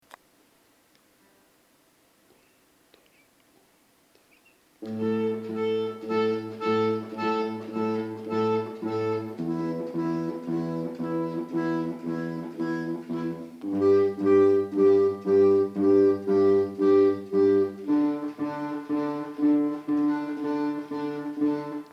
Clarinete en Si bemol
Guitarra
Violín
- Tonalidad: Mi menor
Interpretaciones en directo.
Intro sin piano
Intro_sin_piano.MP3